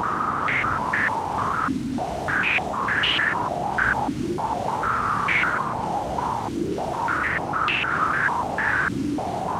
STK_MovingNoiseE-100_01.wav